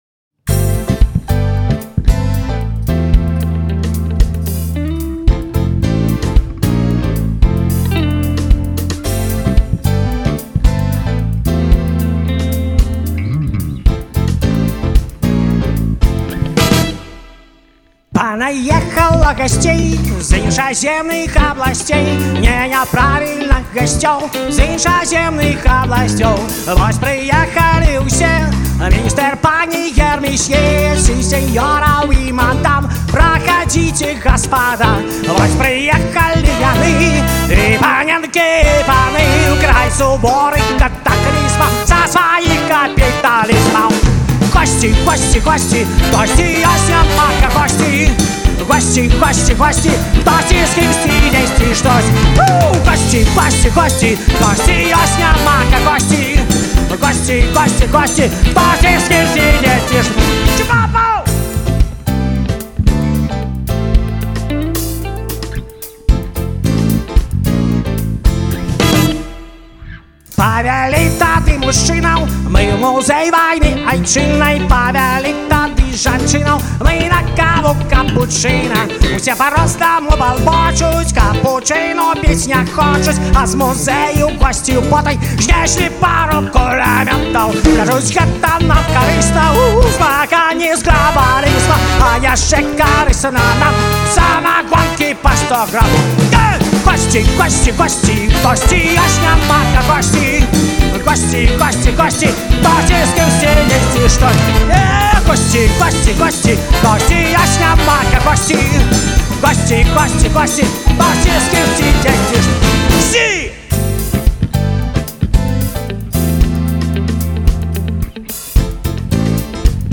У джазавым туры
адмысловая вэрсія гіта